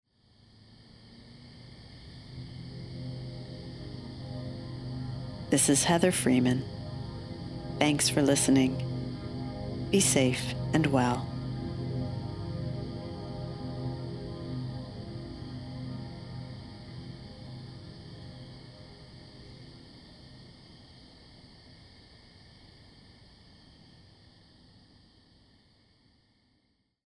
Anyway, some friends on Facebook said they found comfort in my motherly sign-off and that tickled me to bits and made me not-so-secretly very pleased.